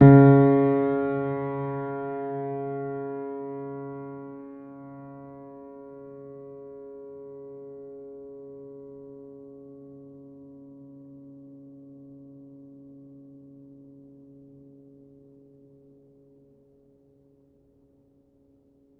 healing-soundscapes/Sound Banks/HSS_OP_Pack/Upright Piano/Player_dyn3_rr1_014.wav at main